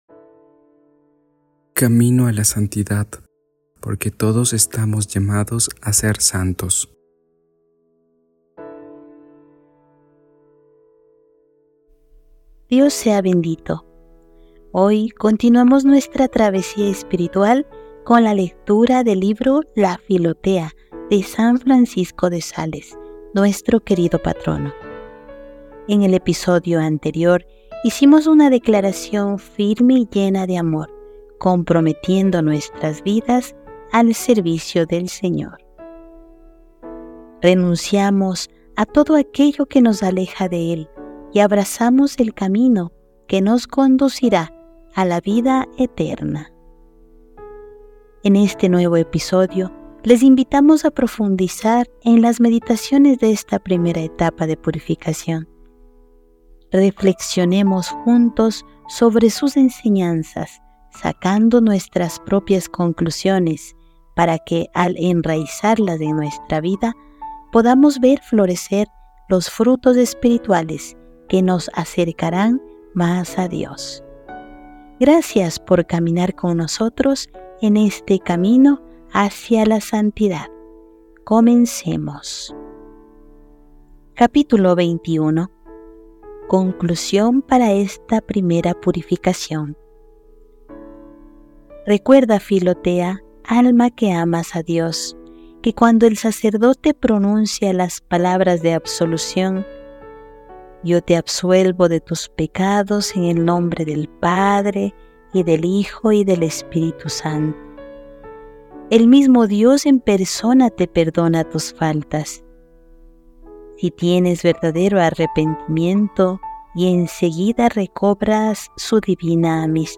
Hoy continuamos nuestra travesía espiritual con la lectura del Libro de la Vida Devota, también conocido como Filotea, de San Francisco de Sales.